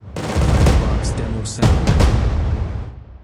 “Drumdoom” Clamor Sound Effect
Can also be used as a car sound and works as a Tesla LockChime sound for the Boombox.
DrumdoomDemo.wav